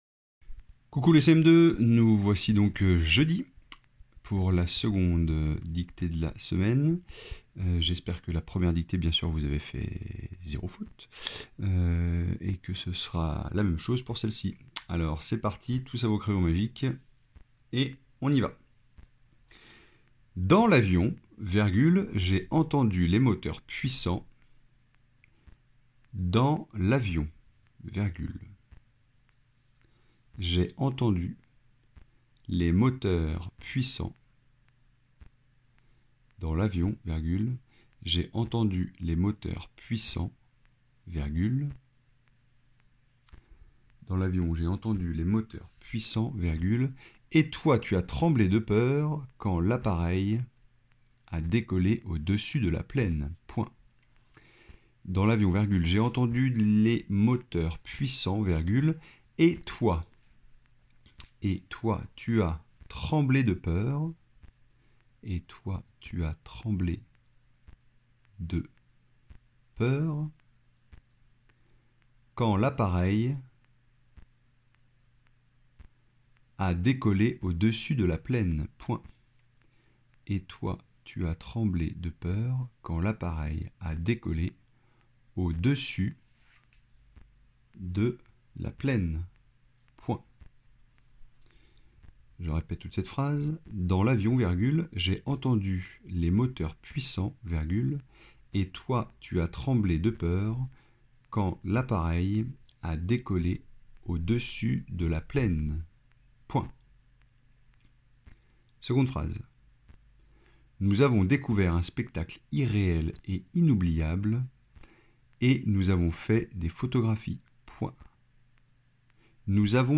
-Dictée :
dicti__BDe-du-jeudi-4.mp3